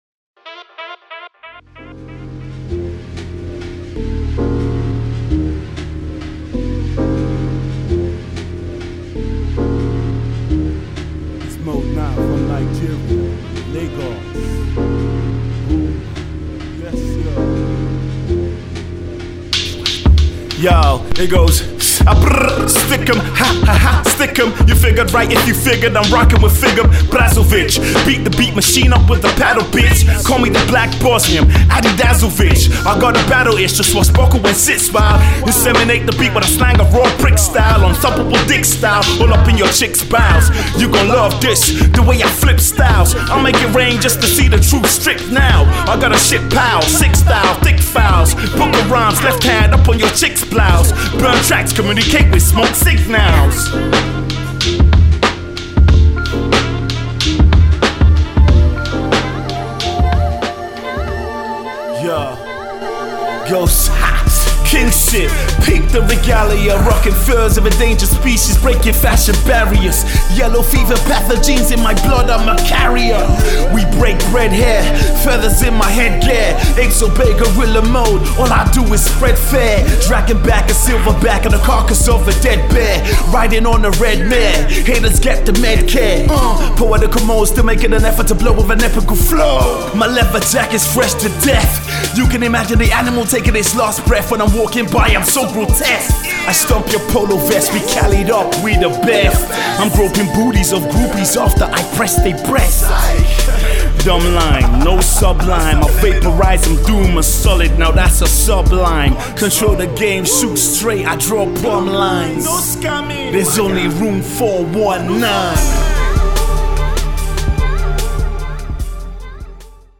Its straight Rawness, download it below.